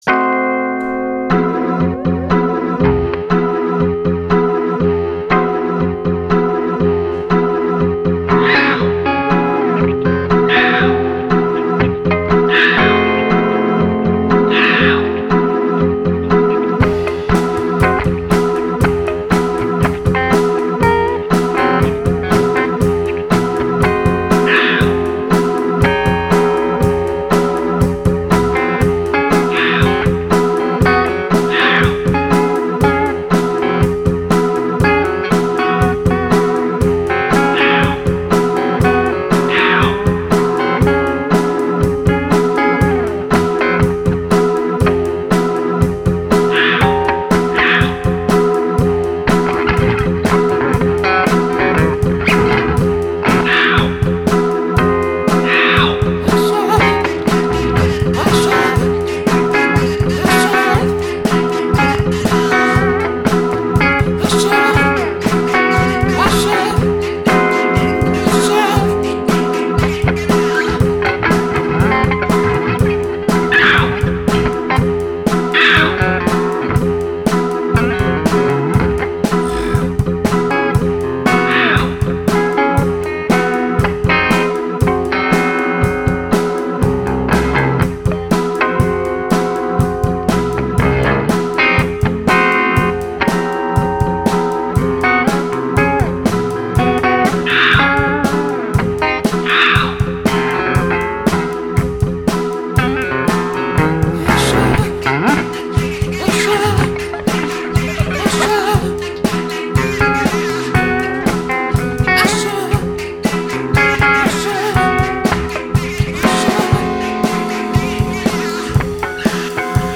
enregistré à Herblay